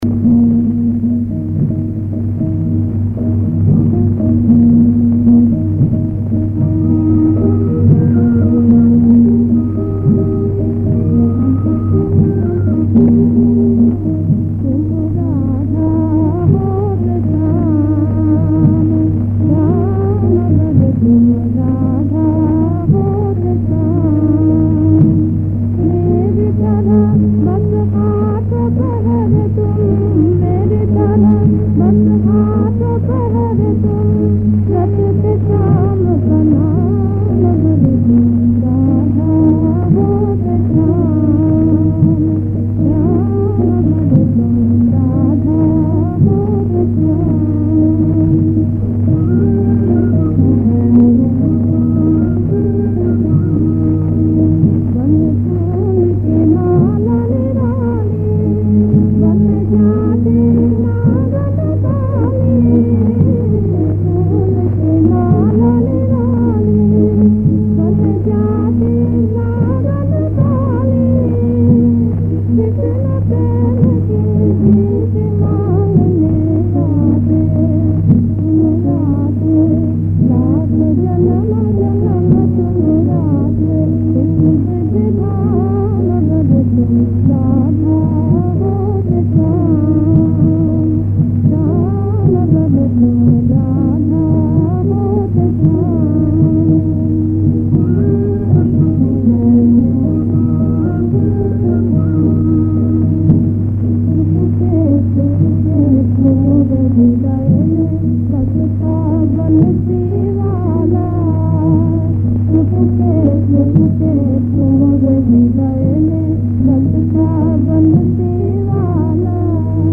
হিন্দি ভজন।